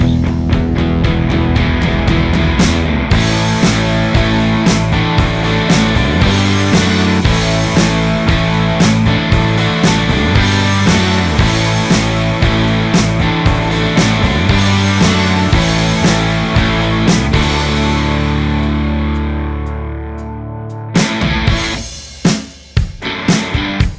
Minus Lead And Solo Guitars Rock 3:29 Buy £1.50